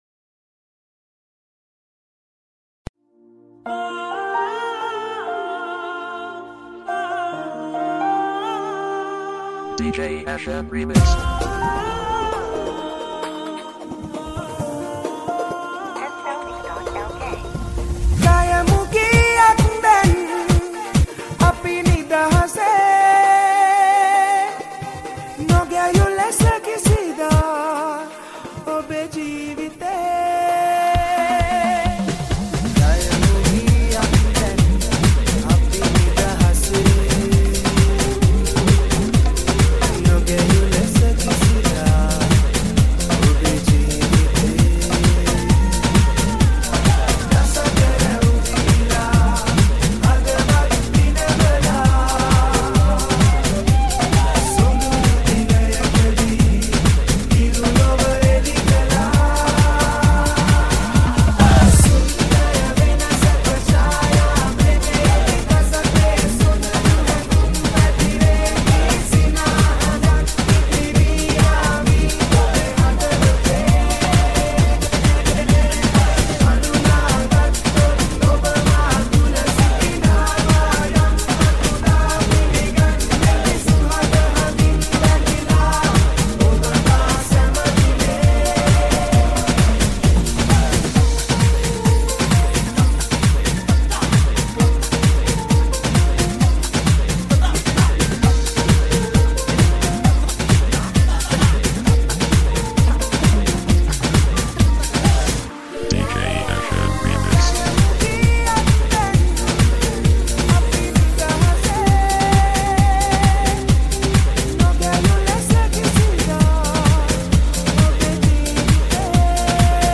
Sinhal DJ Nonstop
Party DJ